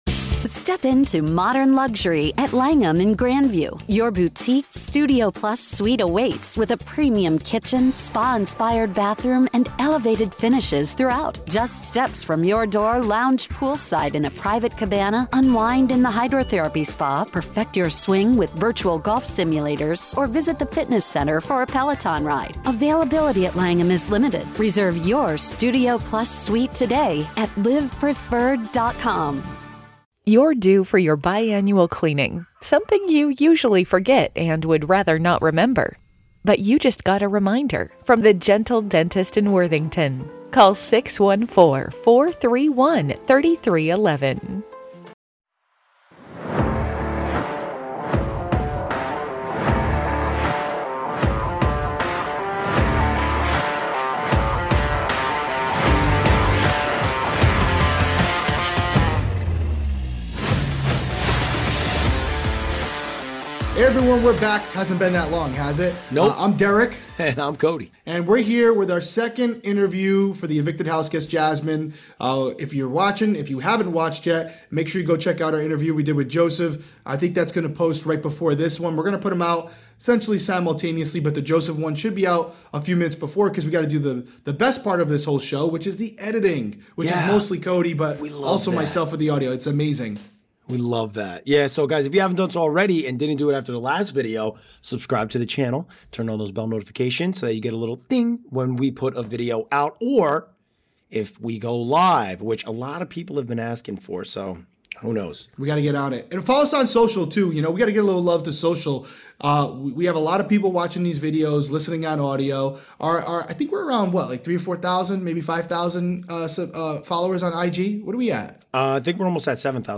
Big Brother 24 | Exit Interview